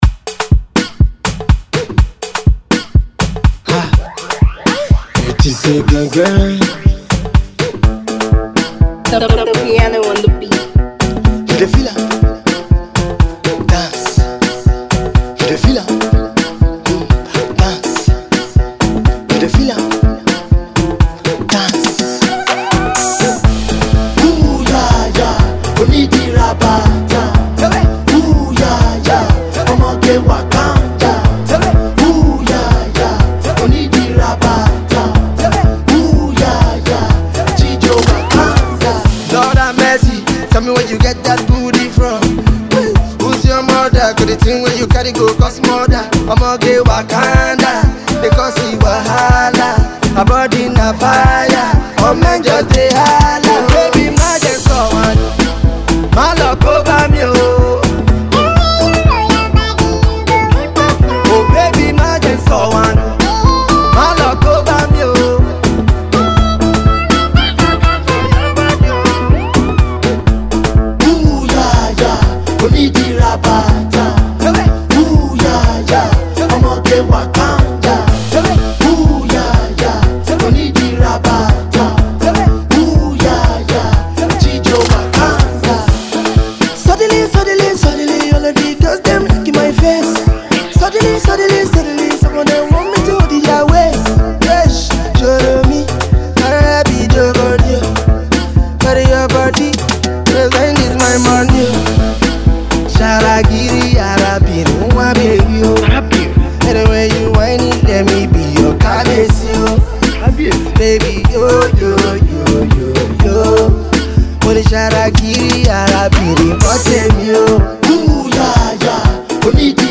dance tune